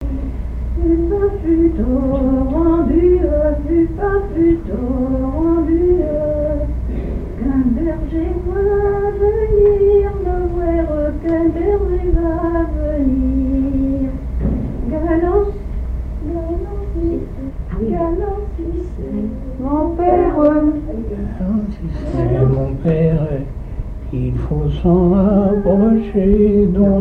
Mémoires et Patrimoines vivants - RaddO est une base de données d'archives iconographiques et sonores.
Genre laisse
répertoire de chansons
Pièce musicale inédite